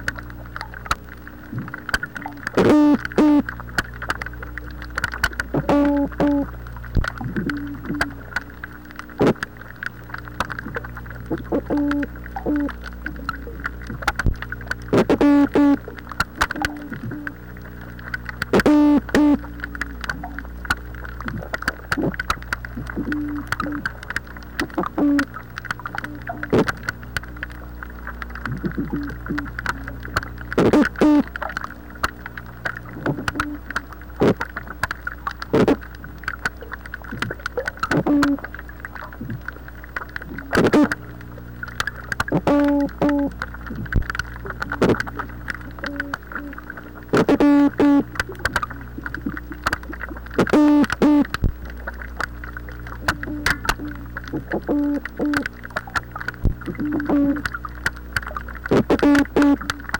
This is what a chorus of many gulf toadfish sounds like
Listen to a chorus of Gulf toadfish